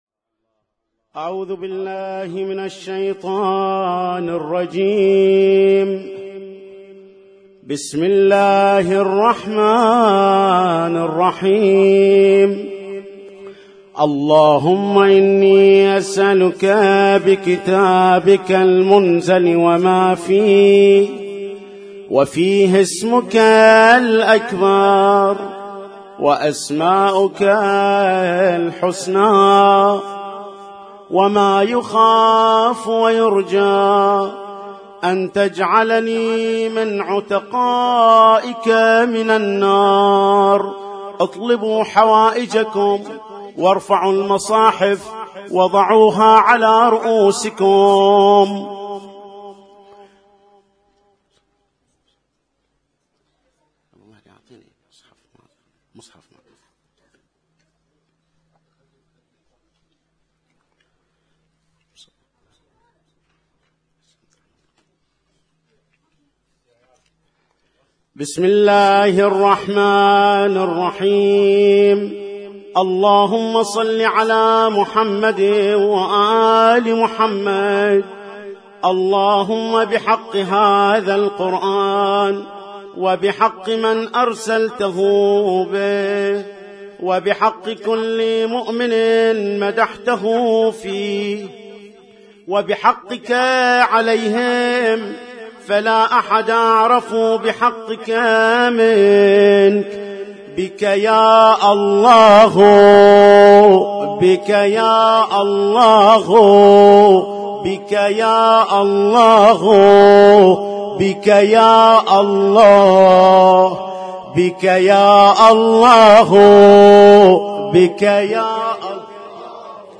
Husainyt Alnoor Rumaithiya Kuwait
اسم التصنيف: المـكتبة الصــوتيه >> الادعية >> ادعية ليالي القدر